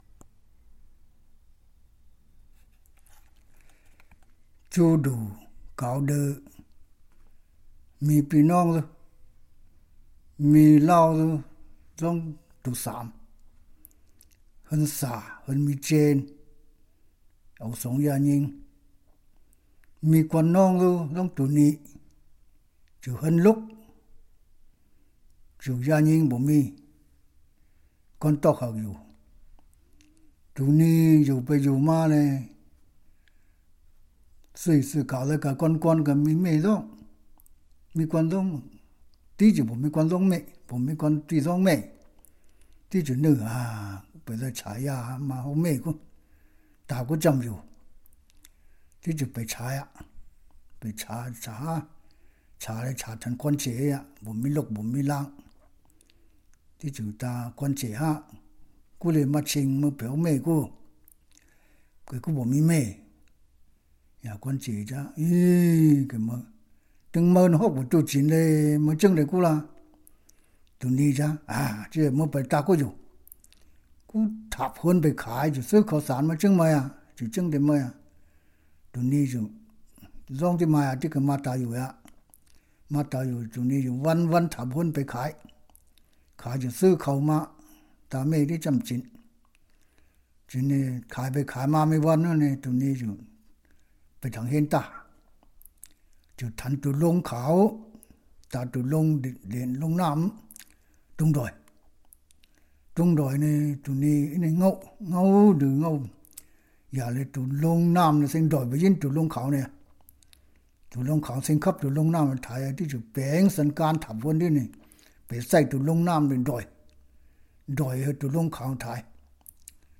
Wenshan Nong Zhuang Stories Southern Zhuang Dialect, Yan-Guang Verncular, as spoken in Xichou County and Zhetu District of Guangnan County More than half of the Zhuang people of Wenshan Prefecture speak the Nong Southern Zhuang dialect, also know as the Yan-Guang Verncular of Southern Zhuang.